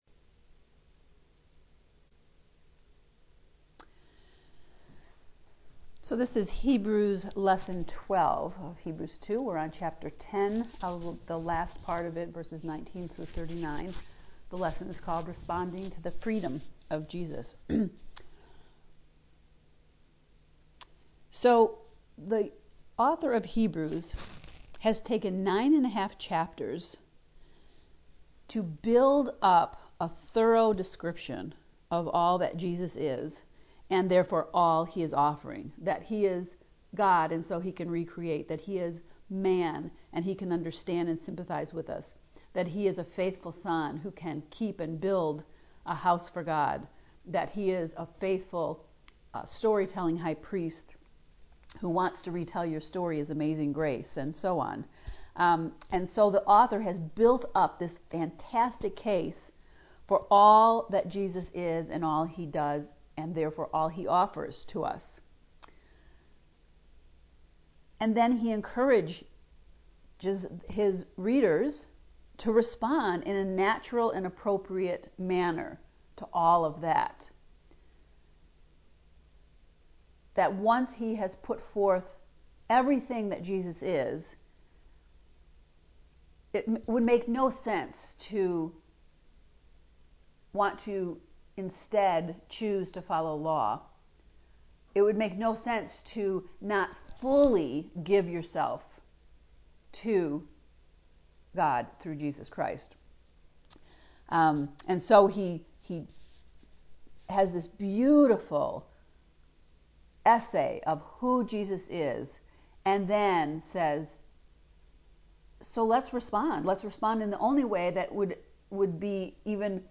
In other words, I taped this alone in my kitchen, with no class!